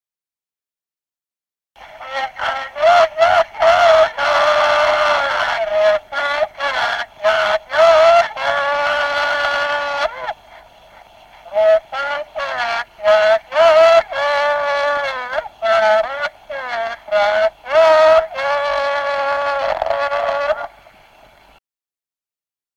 Народные песни Стародубского района «На гряной неделе», гряная.
1951 г., д. Камень.